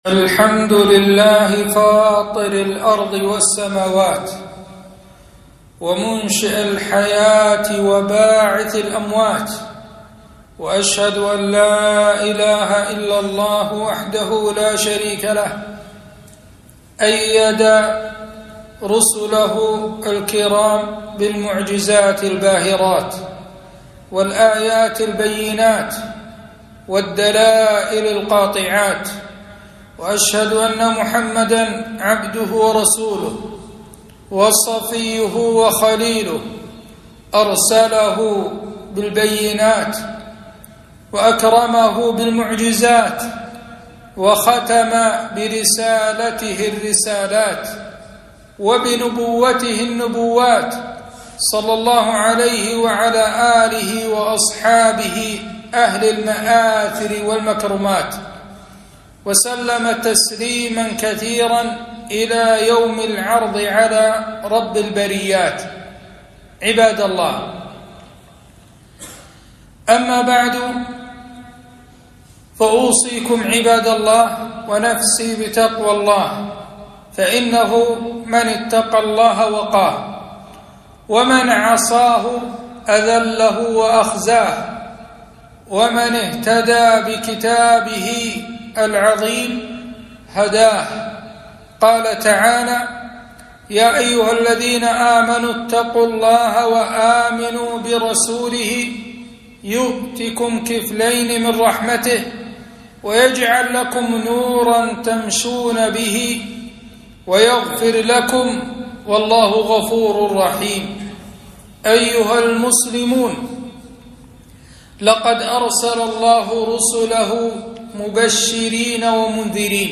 خطبة - معجزات النبي ﷺ